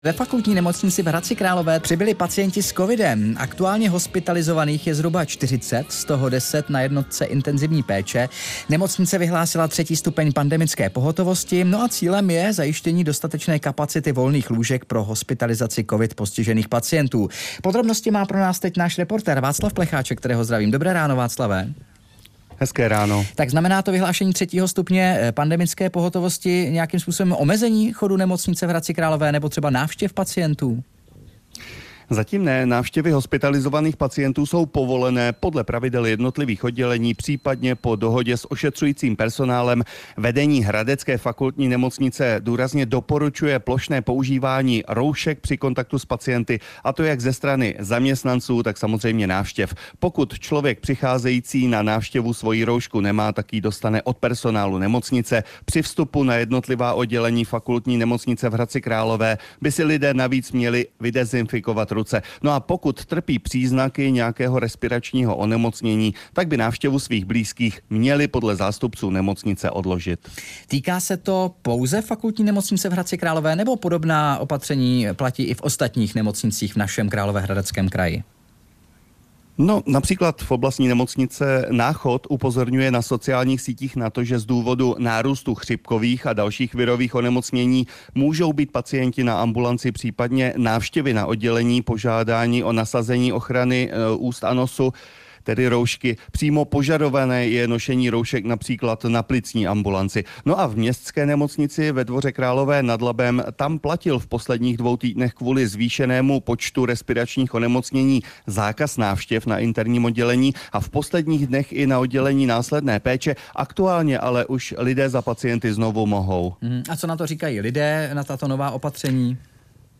Zprávy pro Královéhradecký kraj: Hradecká fakultní nemocnice vyhlásila 3. stupeň pandemické pohotovosti, přibyli pacienti s covidem - 10.10.2024